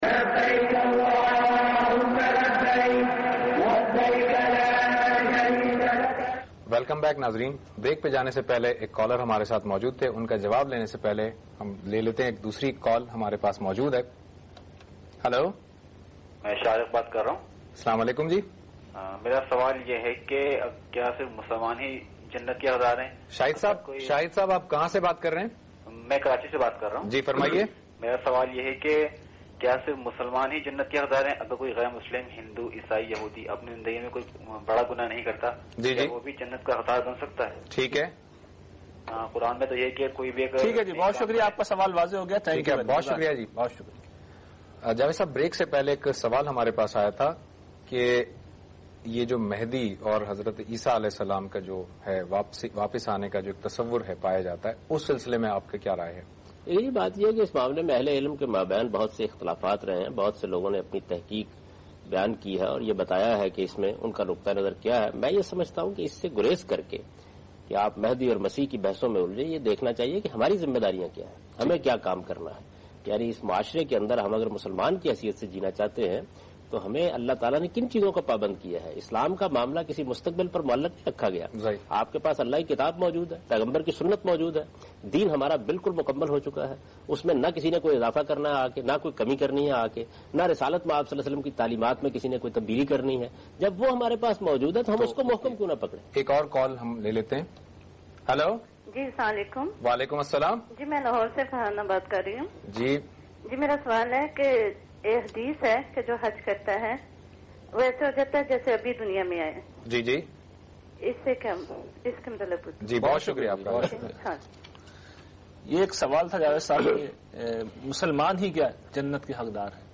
The program was aired on Aaj TV (in Pakistan) in the year 2006.